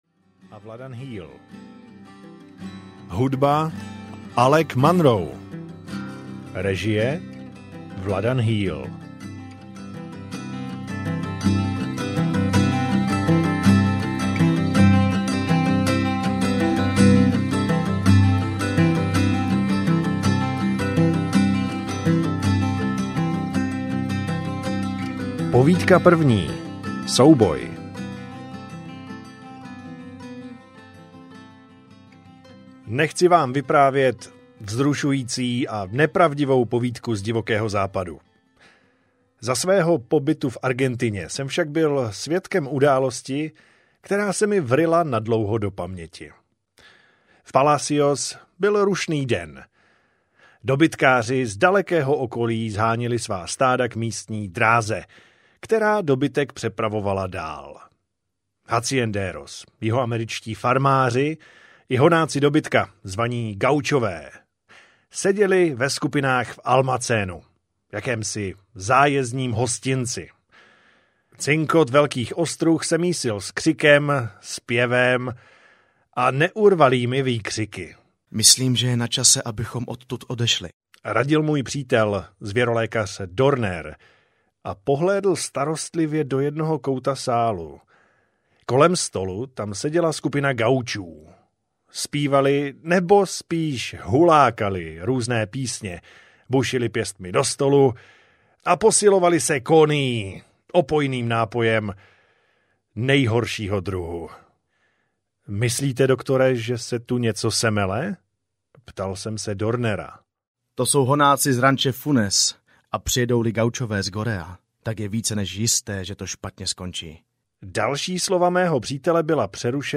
Na vlně 57 metrů audiokniha
Ukázka z knihy